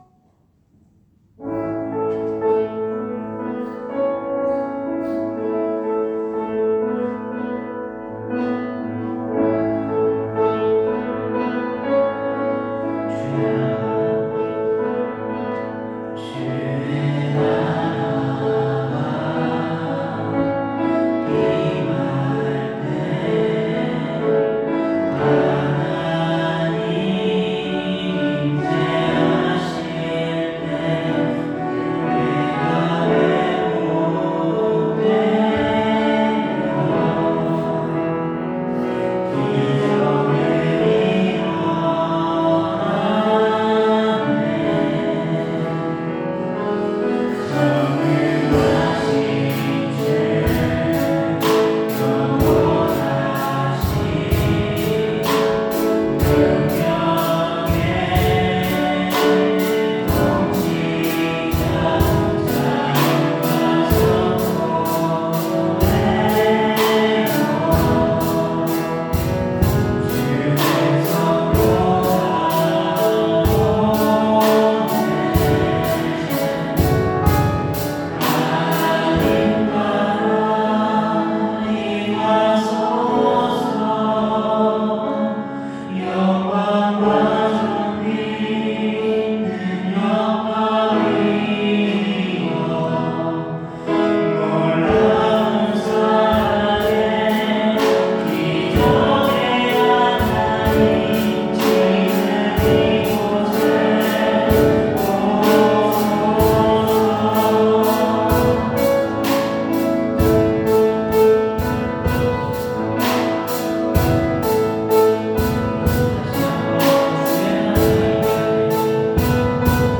2025년 03월 02일 주일찬양